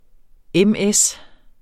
MS eller ms forkortelse uofficiel, men meget almindelig form: M/S Udtale [ ˈεmˈεs ] Betydninger = motorskib Rapportér et problem fra Den Danske Ordbog Den Danske Ordbog .